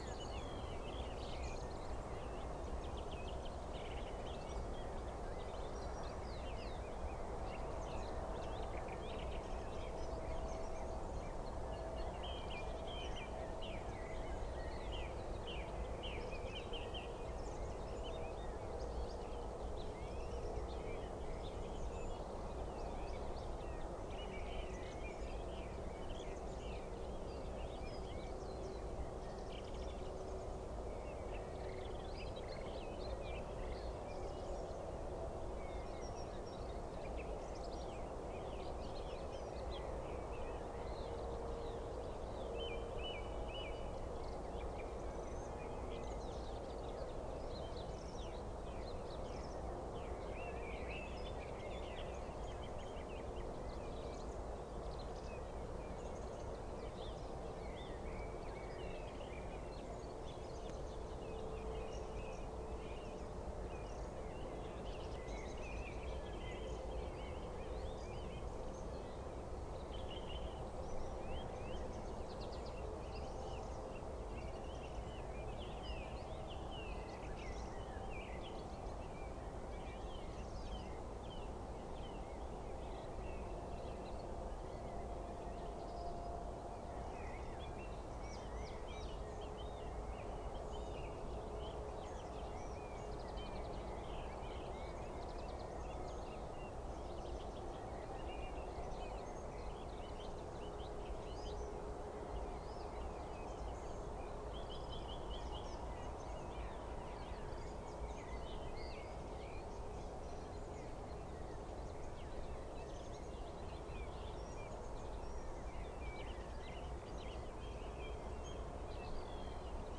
Corvus corone
Turdus philomelos
Sylvia atricapilla
Emberiza citrinella
Alauda arvensis